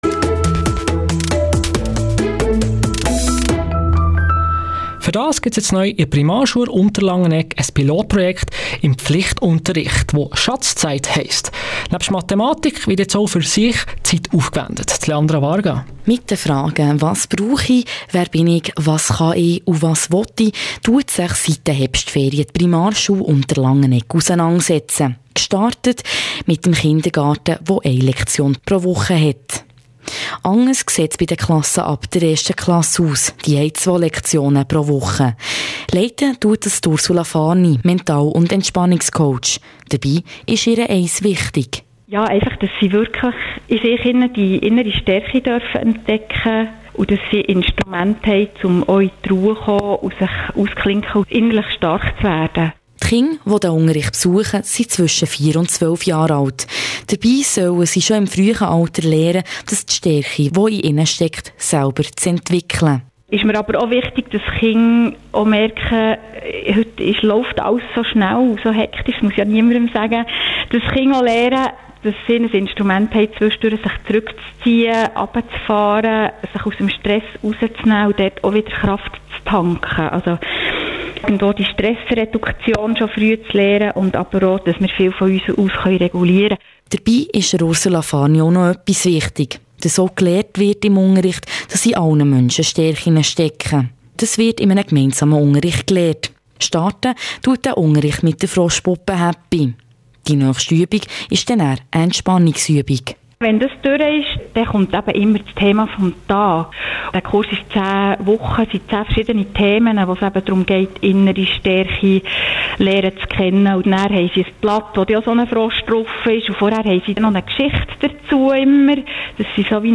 Das Projekt fand breite Beachtung und wurde durch mehrere Medienberichte dokumentiert – insgesamt erschienen mehrere Artikel, unter anderem in der Berner Zeitung und im Thuner Tagblatt und sogar ein Interview auf Radio BEO.
Artikel-radio-beo.mp3